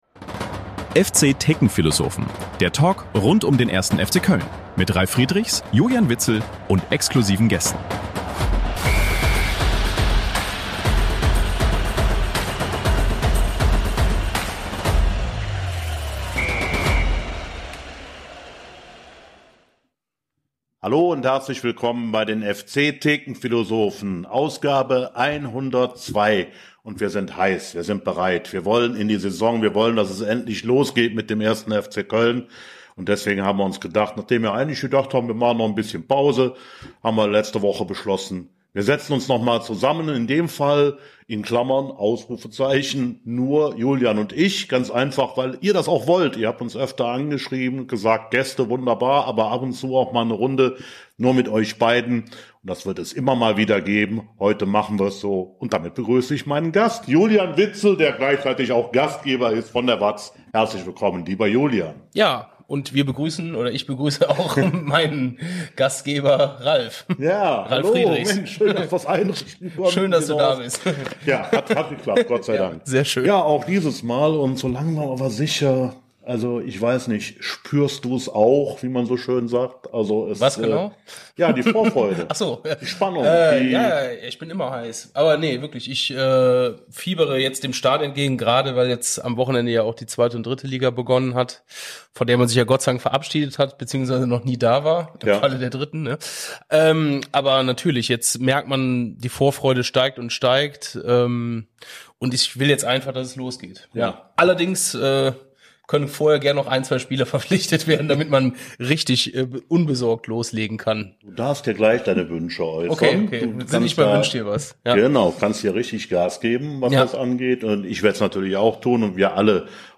- Folge 102 ~ FC-Thekenphilosophen - Der Talk Podcast